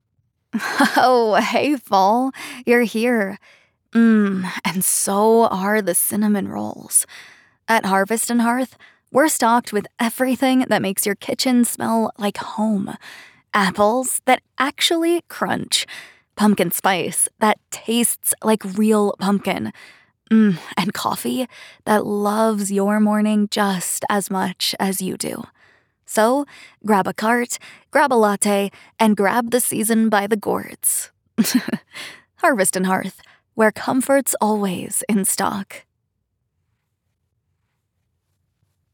Commercial // Warm, Bright